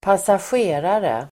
Uttal: [²pasasj'e:rare]